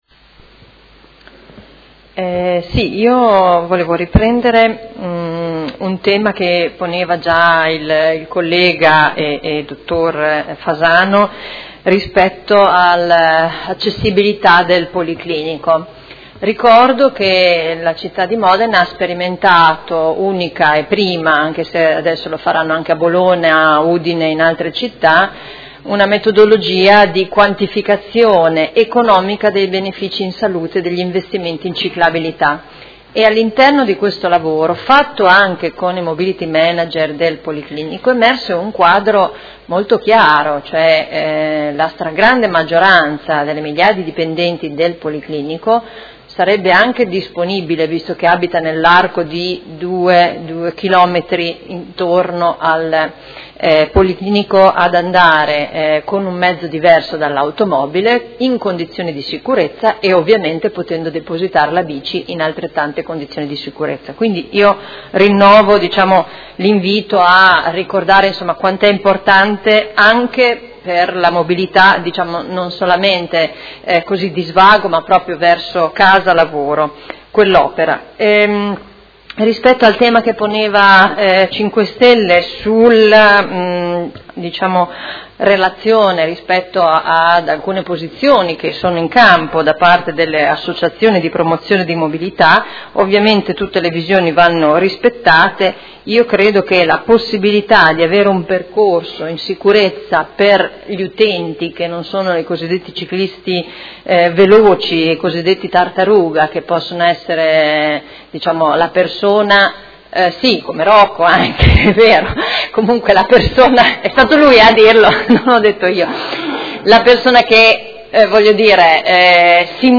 Seduta del 11/05/2015 Replica a risposta Assessore Arletti. Interrogazione dei Consiglieri Arletti, Carpentieri e Fasano (PD) avente per oggetto: Ciclabile su Via Emilia Est – quali i tempi per dare continuità al percorso.